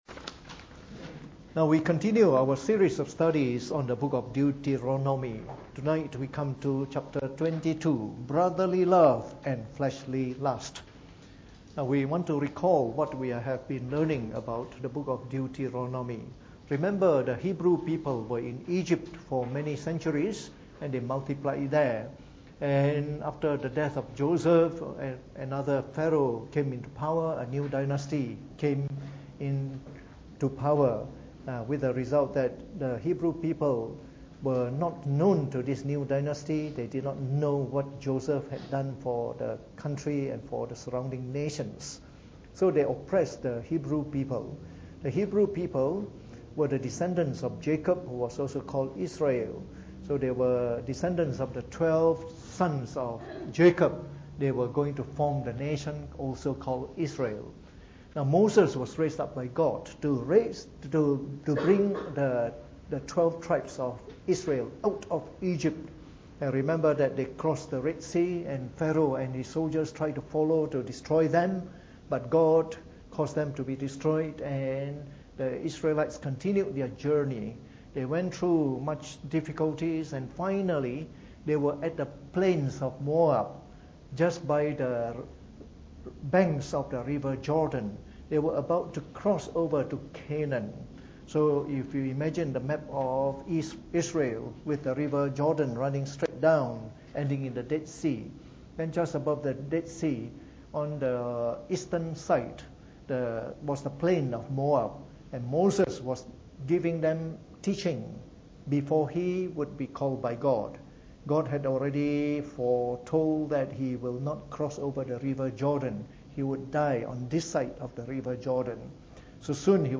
Preached on the 11th of July 2018 during the Bible Study, from our series on the book of Deuteronomy.